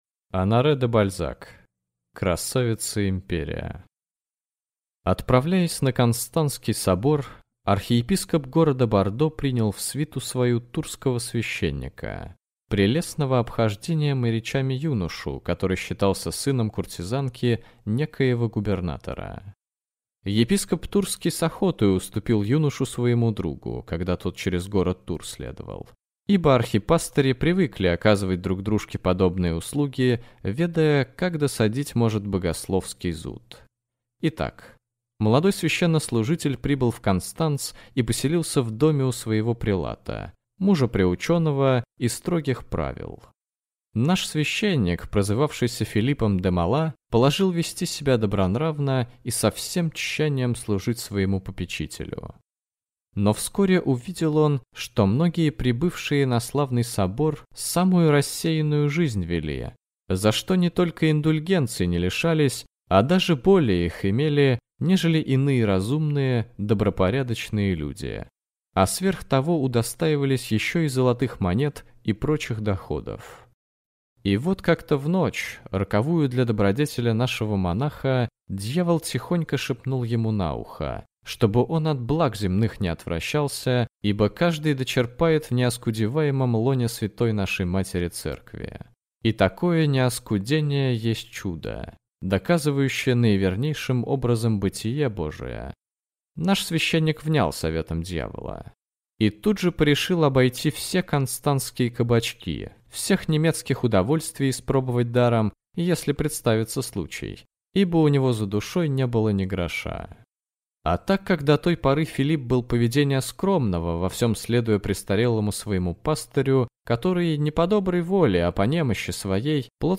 Аудиокнига Невольный грех (сборник) | Библиотека аудиокниг